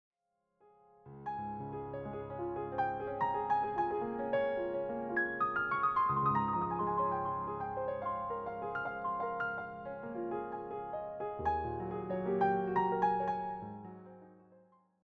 all arranged and performed as solo piano pieces.